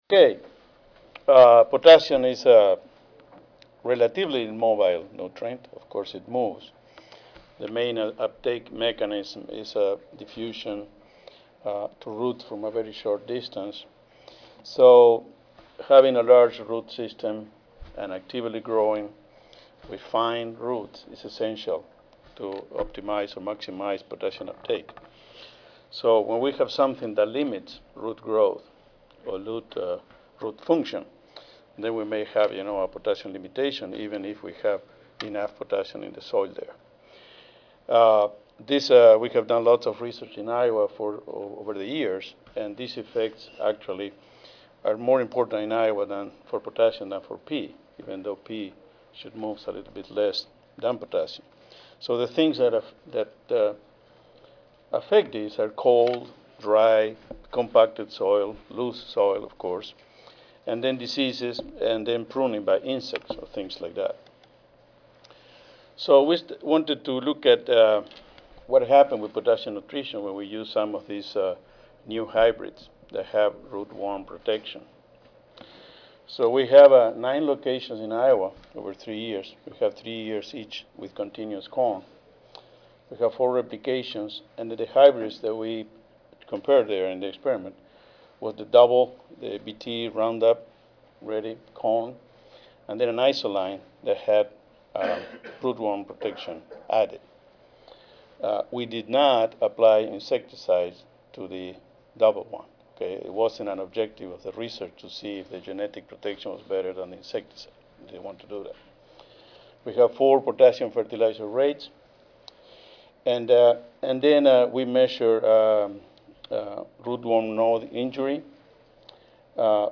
Agrium Advanced Technologies Audio File Recorded presentation